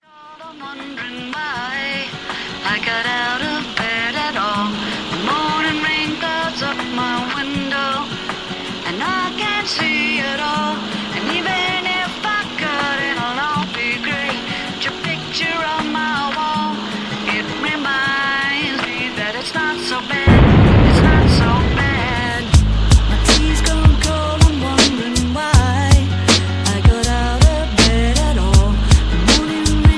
karaoke, sound tracks, rap, r and b, backing tracks, rock